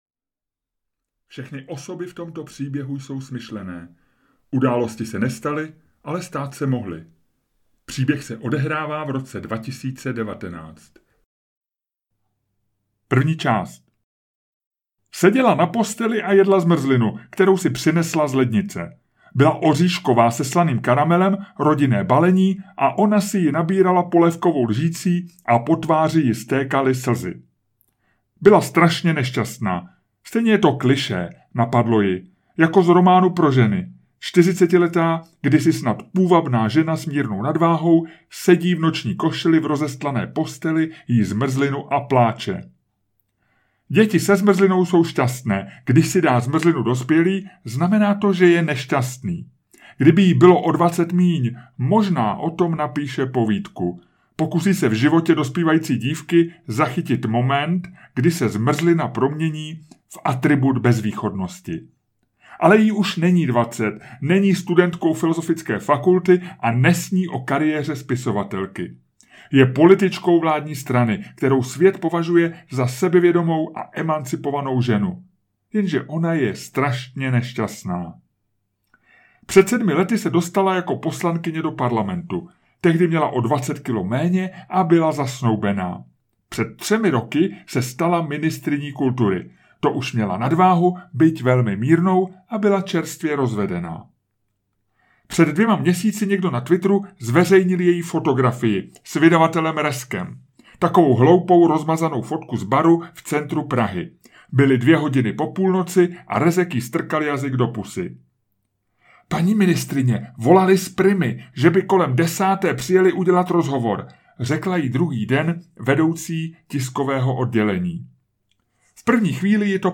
Poslední tweet naštvaného klauna audiokniha
Ukázka z knihy